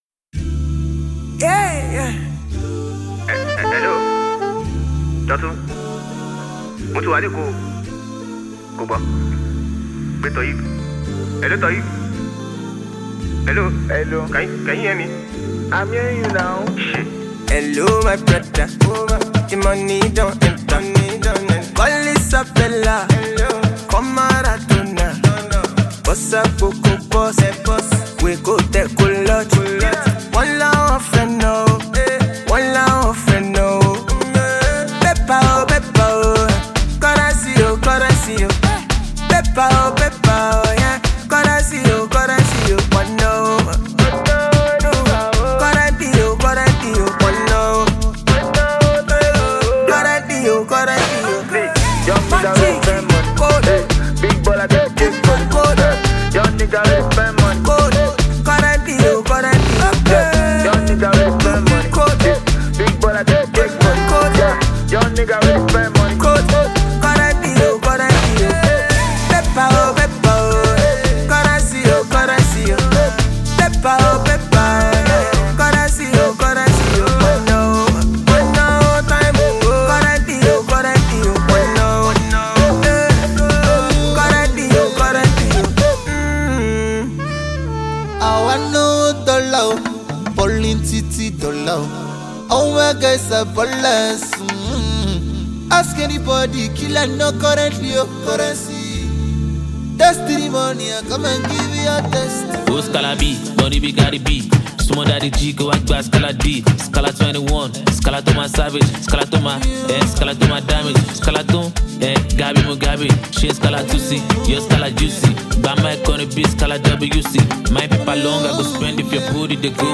The new amapiano infused single